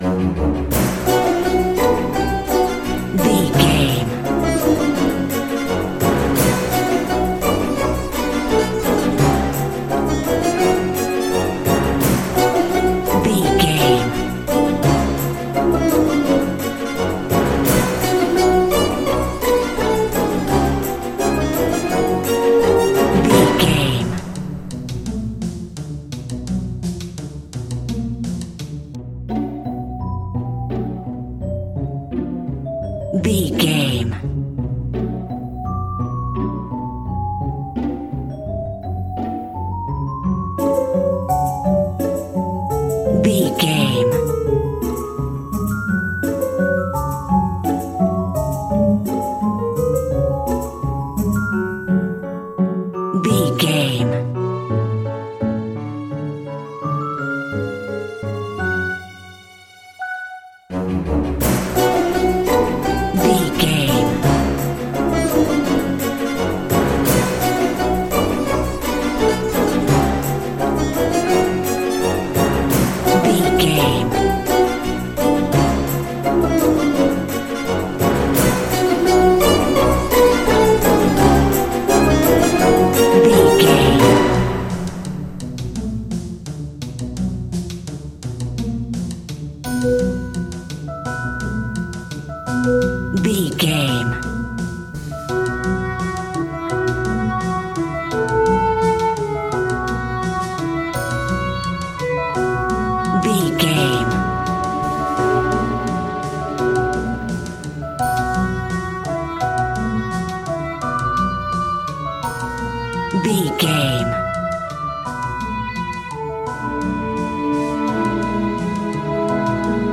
Uplifting
Aeolian/Minor
F#
percussion
flutes
piano
orchestra
double bass
silly
circus
goofy
comical
cheerful
perky
Light hearted
quirky